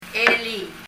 » yesterday 昨日 elii [ɛliy] 英） yesterday 日） 昨日 er a tutau er a elii yesterday morning 昨日の朝 Leave a Reply 返信をキャンセルする。